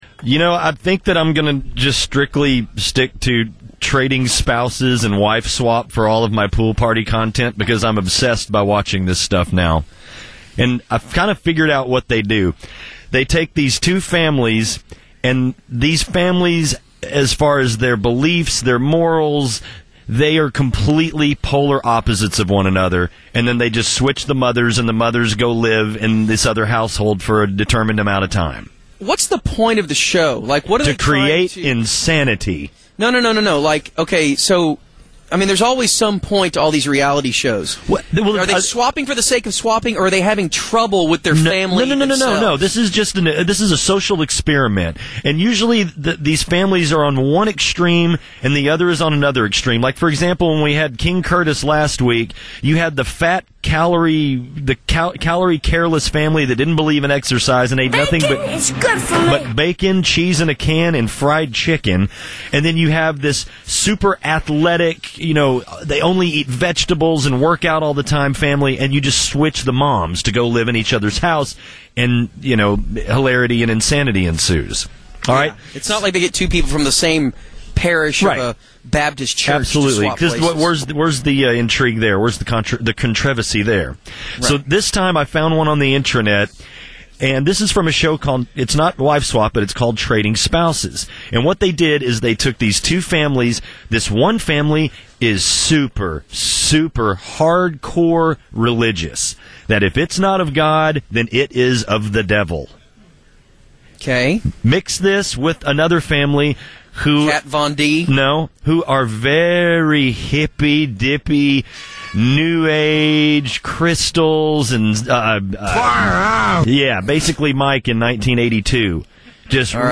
If you have seen it, it’s still entertaining to count the number of times she says “dork-sided”, “gorgeyels”, and “porty”.